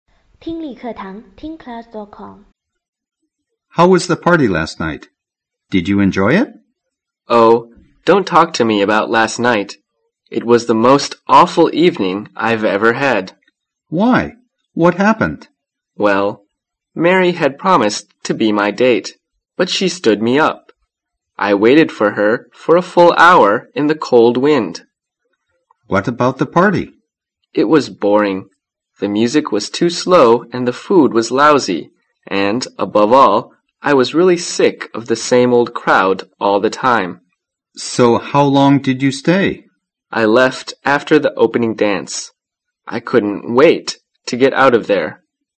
谈论派对感谢英语对话-锐意英语口语资料库11-8